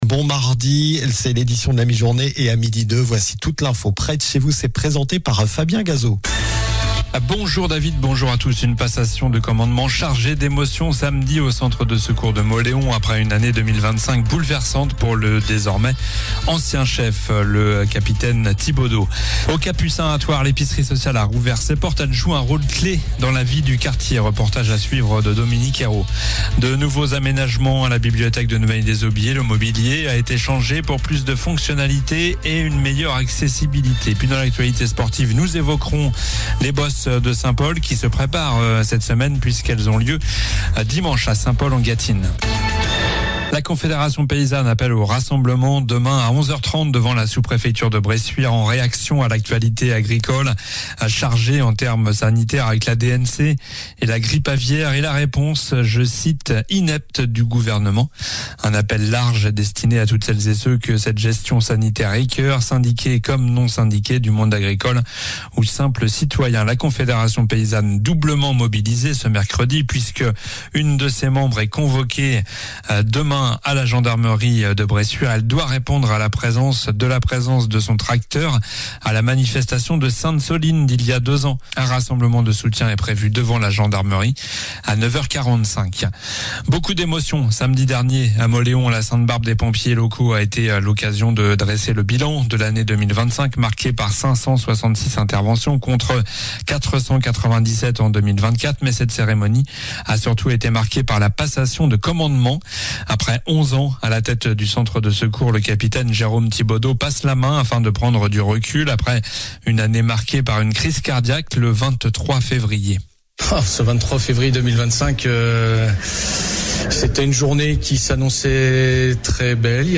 Journal du mardi 9 décembre (midi)